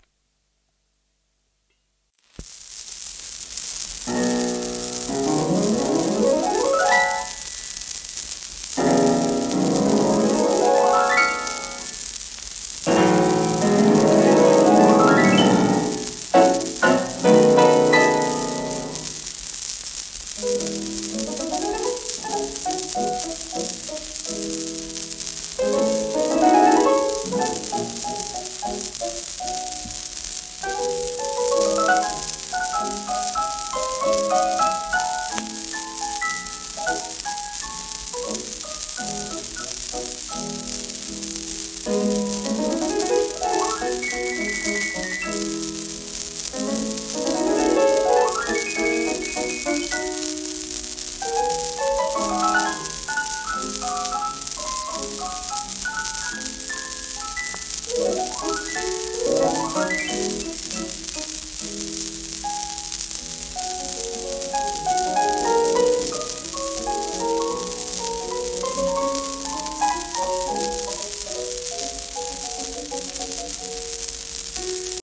盤質A- *レーベルキズ、小キズ、一部に薄いスレ
1937年録音